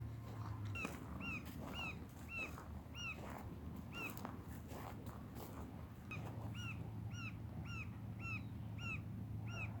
Colaptes melanochloros melanolaimus
English Name: Green-barred Woodpecker
Detailed location: Lago Salto Grande
Condition: Wild
Certainty: Observed, Recorded vocal